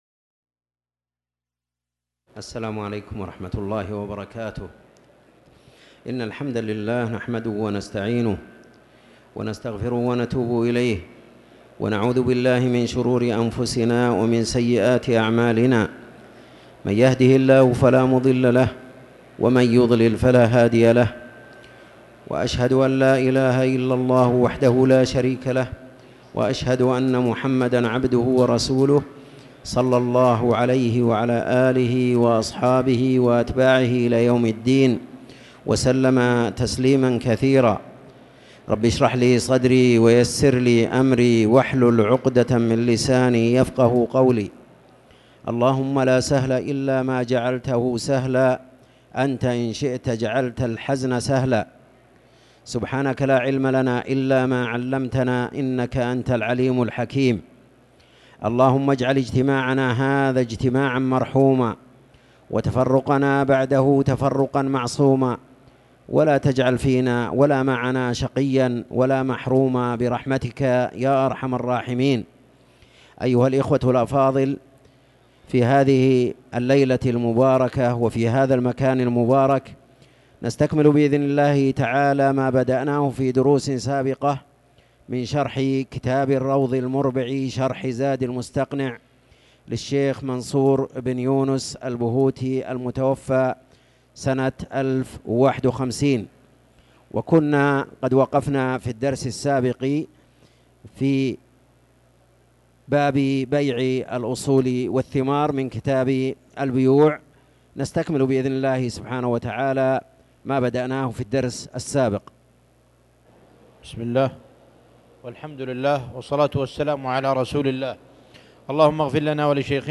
تاريخ النشر ٥ رجب ١٤٤٠ هـ المكان: المسجد الحرام الشيخ